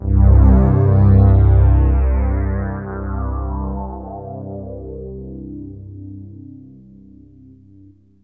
AMBIENT ATMOSPHERES-5 0007.wav